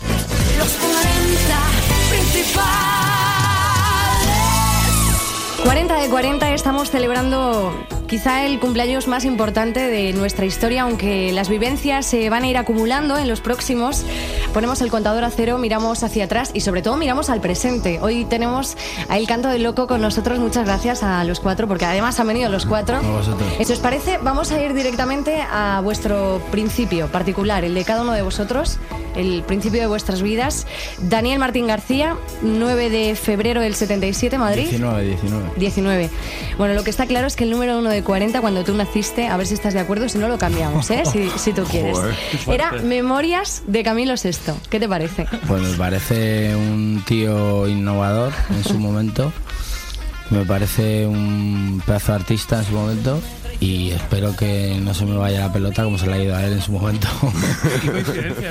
Indicatiu del programa, inici de l'entrevista a Daniel Martín del grup "El canto del loco"
Musical